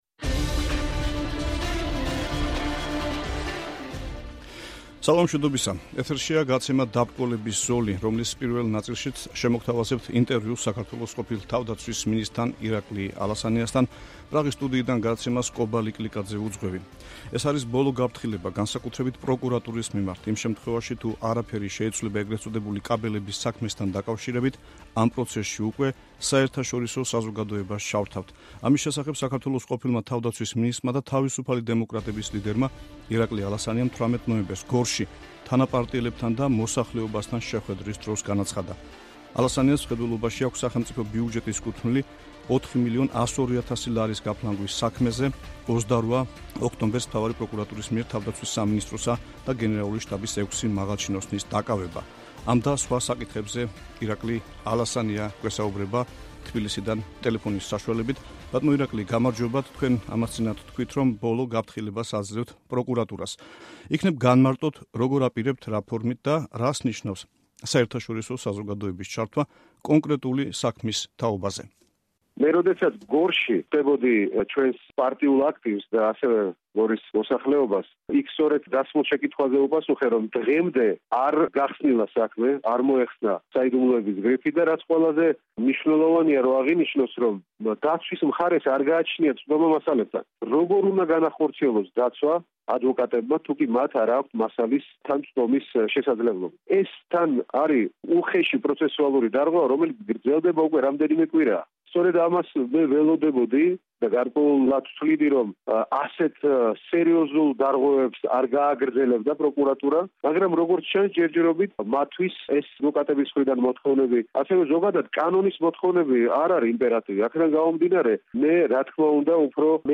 საუბარი ირაკლი ალასანიასთან